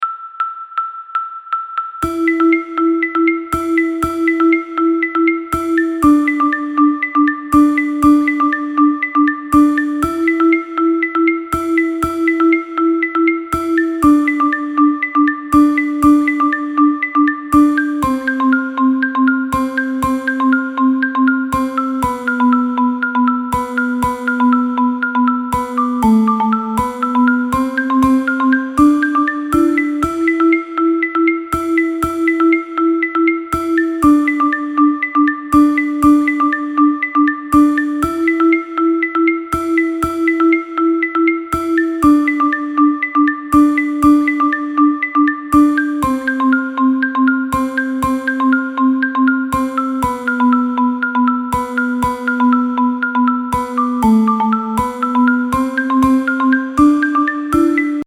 1ループ30秒弱。
パズルゲームに使うBGMではない。
何となく雪マップかな？とか思った。